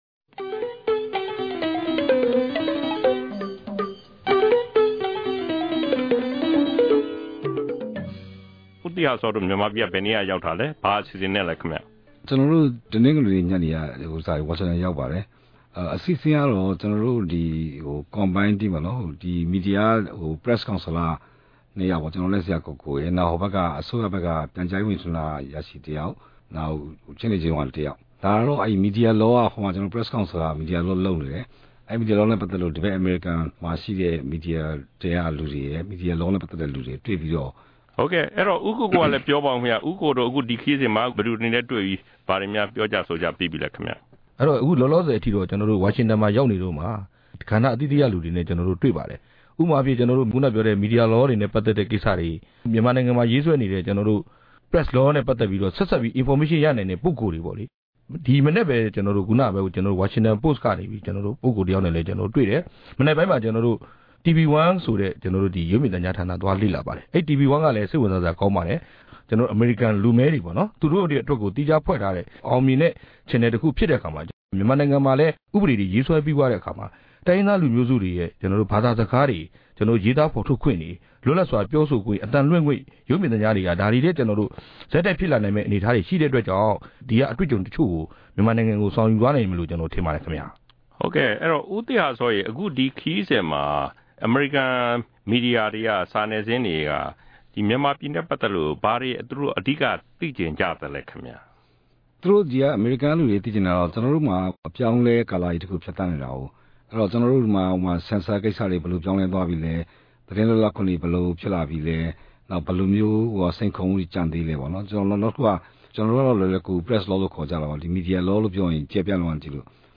အဲဒီအယ်ဒီတာ ၂ ဦး၊ ပြီးခဲ့တဲ့ ဖေဖေါ်ဝါရီလ ၂၁ ရက် ကြာသပတေးနေ့က RFA ကို လာရောက်လည်ပတ်ခဲ့စဉ်၊ စတူဒီယို အမှတ် ၁၁ မှာ မေးမြန်းပြောကြားခဲ့တာတွေကို ဒီအပတ် RFA တနင်္ဂနွေစကားဝိုင်းမှာ နားဆင်ပါ။